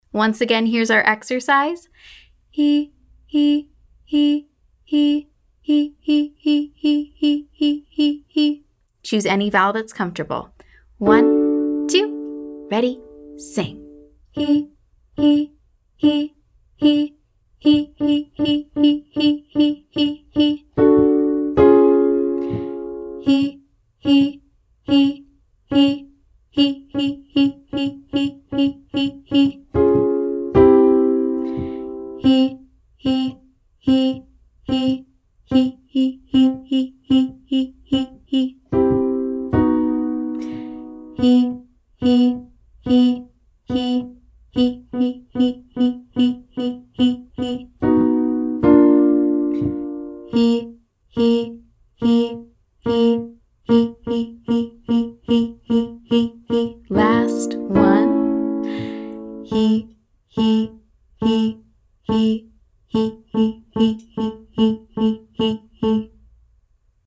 Warmup
Exercise: Staccato quarters & eighths
And last exercise for clarity and accuracy: staccato articulation on the same pitch.
We’ll sing quarter notes, then eighth notes.